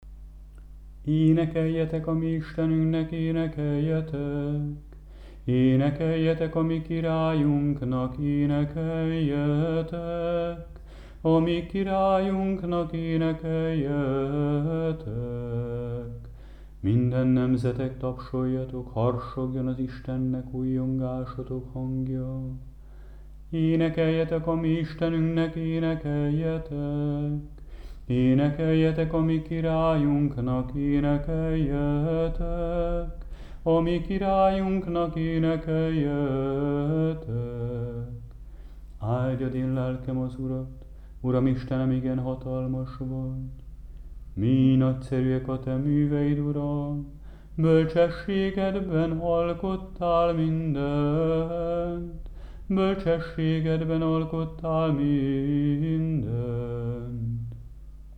11. Prokimen 3. és 4. hang, feltám. és aug. 6..mp3